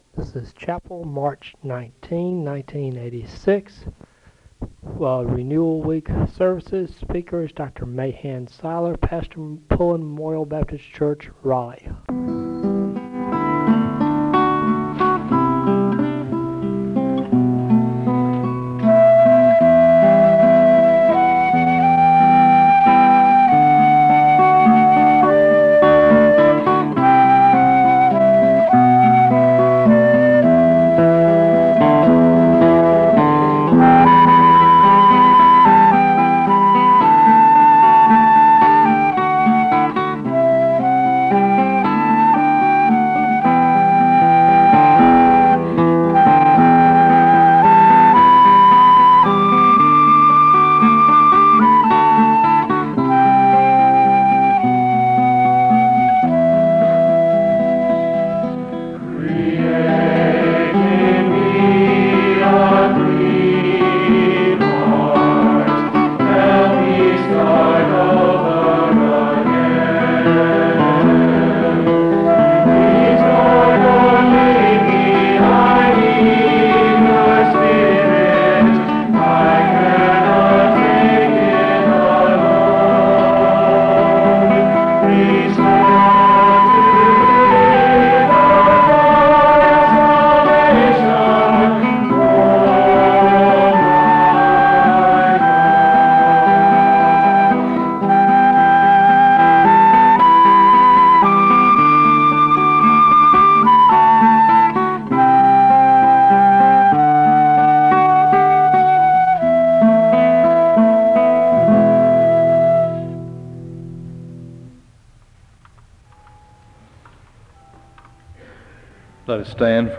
The service begins with a song of worship (0:00-1:56).
SEBTS Chapel and Special Event Recordings